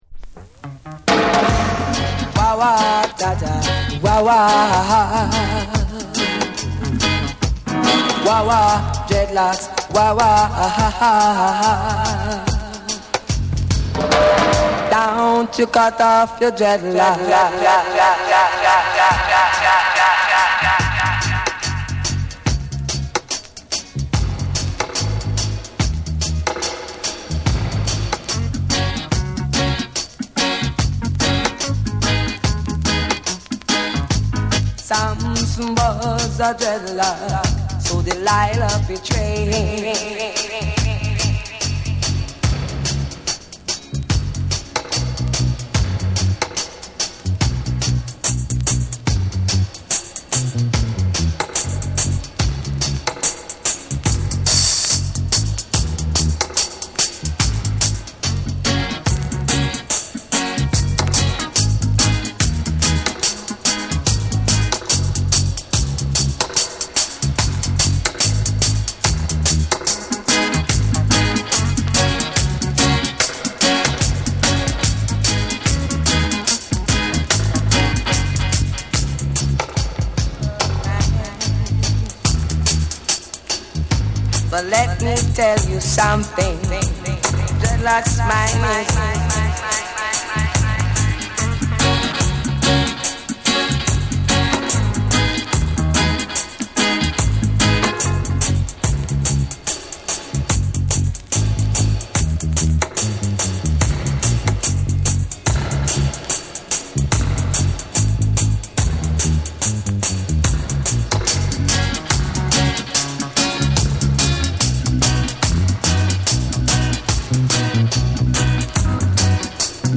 鋭いベースと宇宙的エコーが全編を貫き
REGGAE & DUB